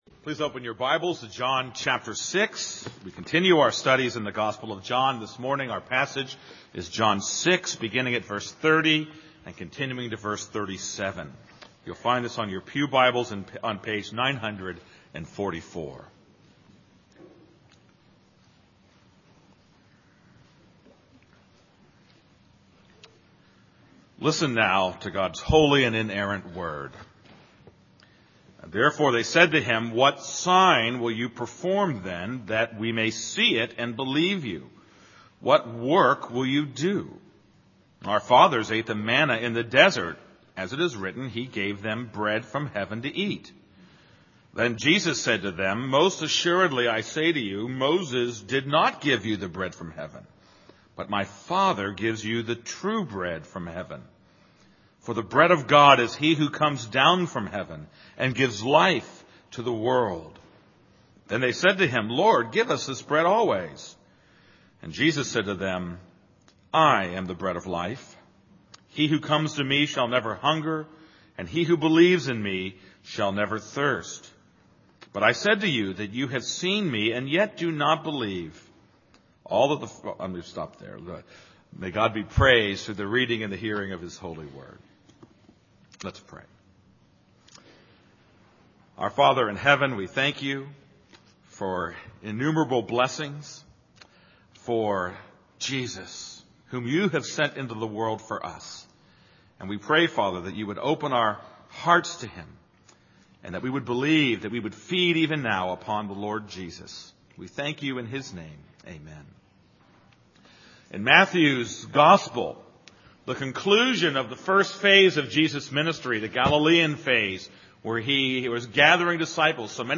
This is a sermon on John 6:30-37.